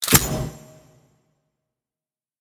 pause-continue-click.ogg